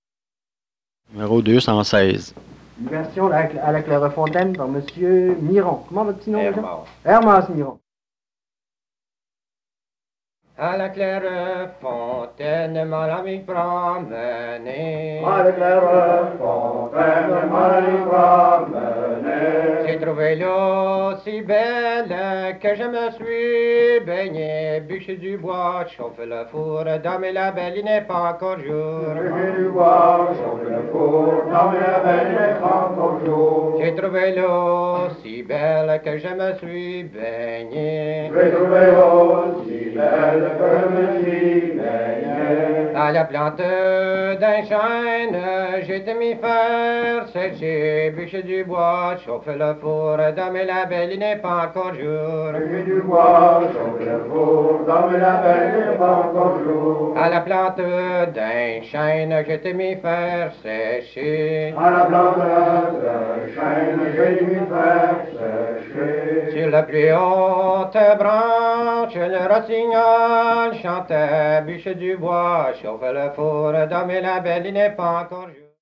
Collection du Centre franco-ontarien de folklore.